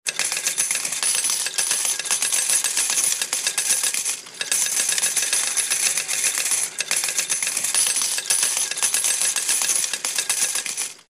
Звуки денег
Монеты сыплются из игрового автомата